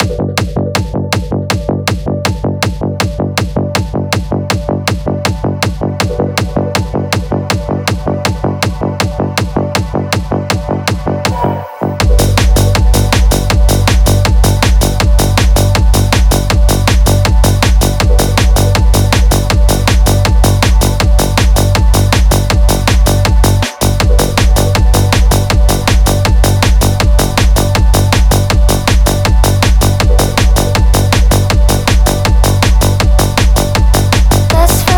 Жанр: Танцевальные / Техно